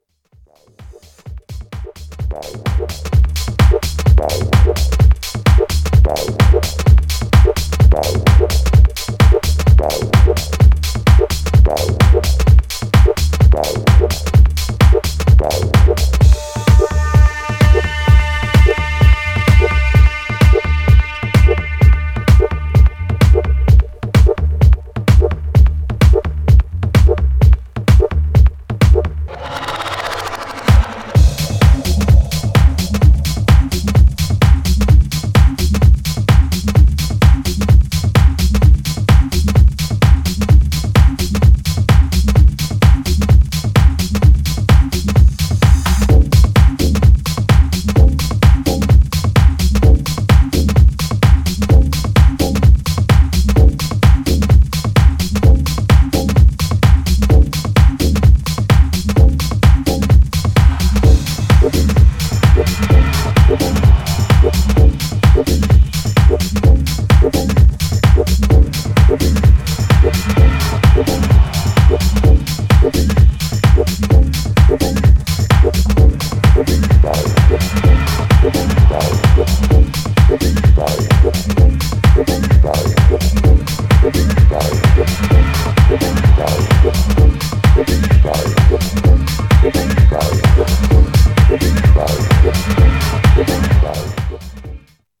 Styl: House